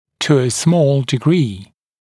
[tu ə smɔːl dɪ’griː][ту э смо:л ди’гри:]в небольшой степени